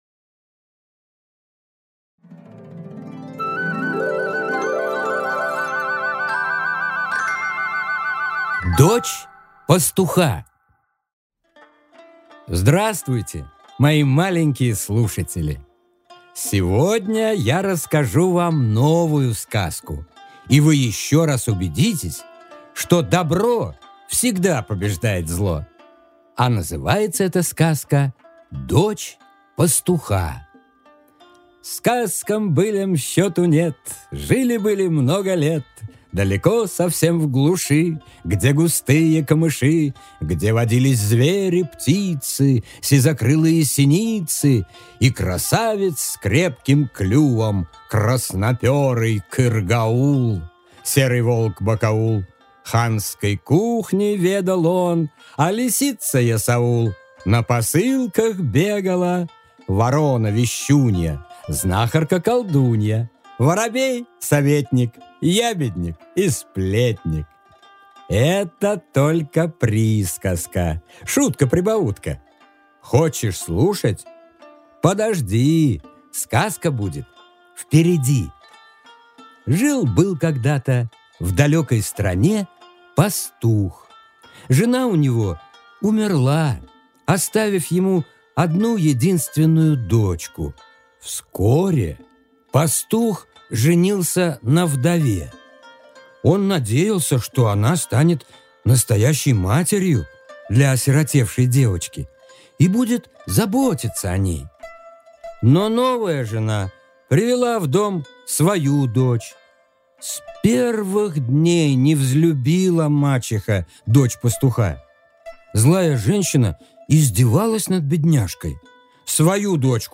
Аудиокнига Дочь пастуха | Библиотека аудиокниг